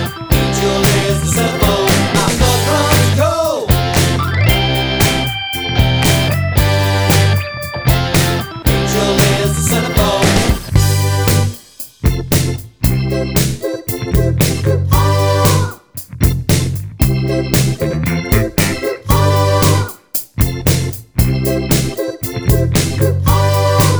Minus Lead Guitar Pop (1980s) 3:34 Buy £1.50